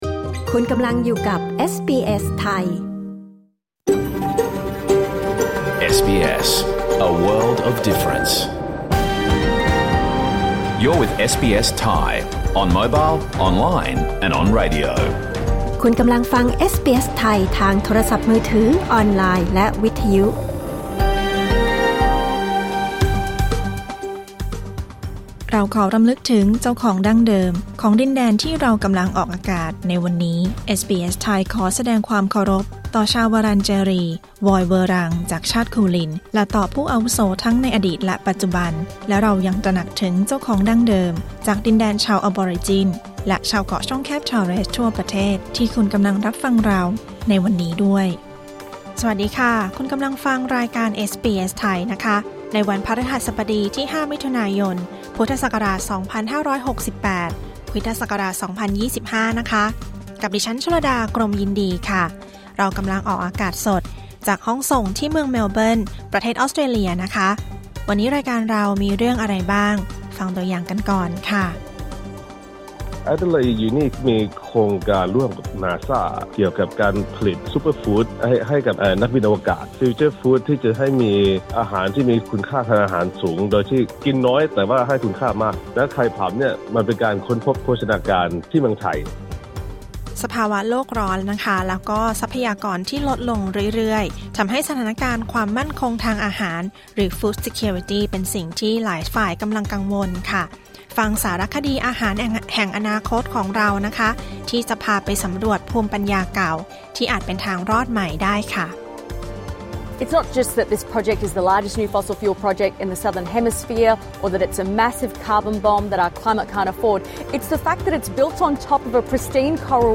รายการสด 5 มิถุนายน 2568